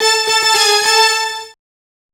Synth Lick 49-01.wav